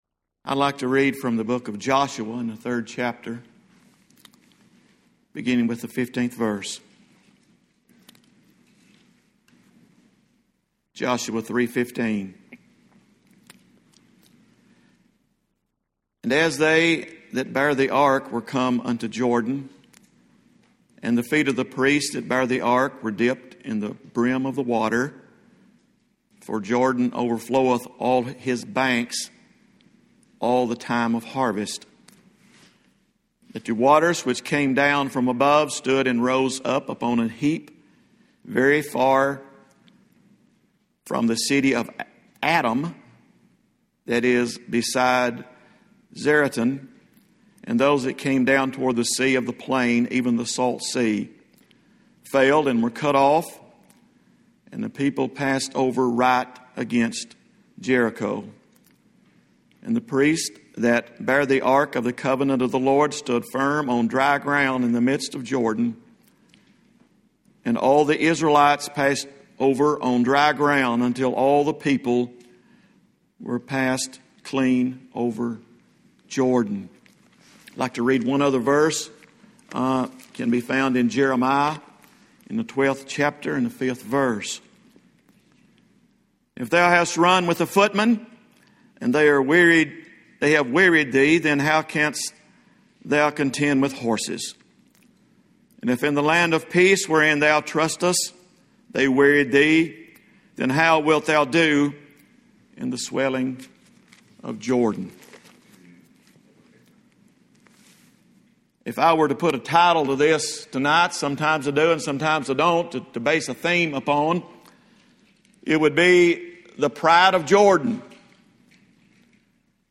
Revival service from 2020-07-22 at Old Union Missionary Baptist Church in Bowling Green, Kentucky.